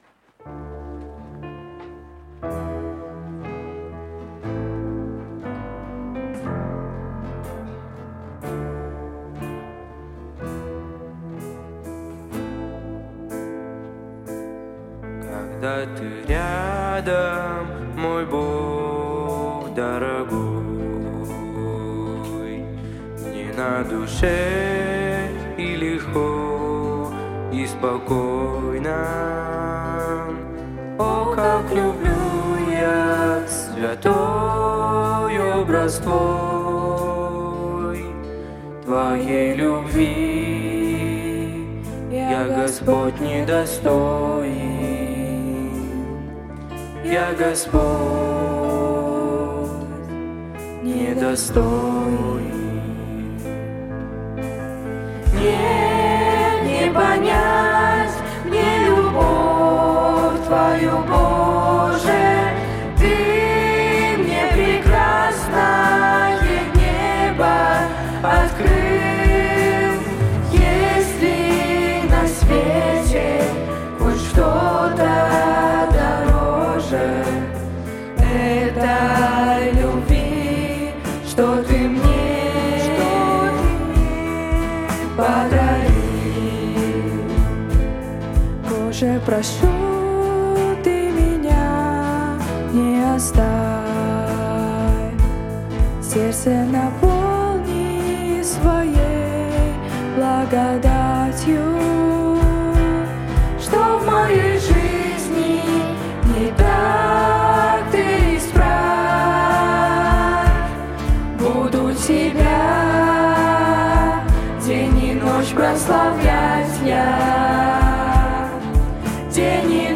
Музыкальная группа
вакал
гітара
клавішы
скрыпка
бас-гітара
ударныя
саксафон